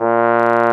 Index of /90_sSampleCDs/Roland L-CDX-03 Disk 2/BRS_Trombone/BRS_Tenor Bone 4